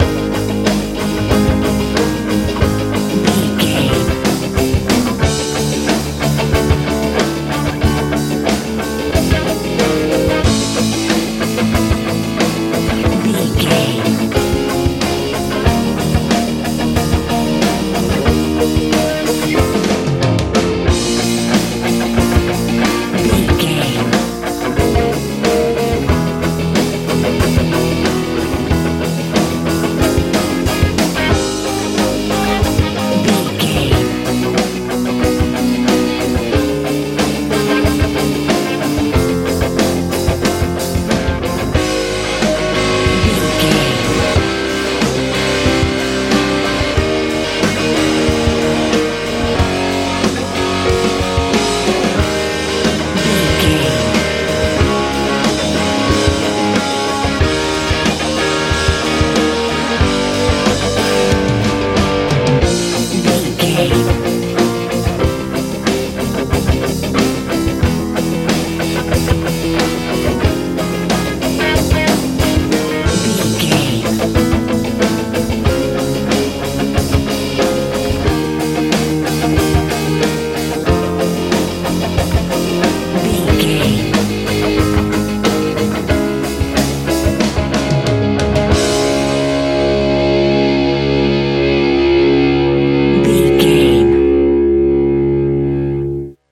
rock feel
Ionian/Major
A♭
dramatic
heavy
piano
electric guitar
bass guitar
drums
80s
powerful
positive
hard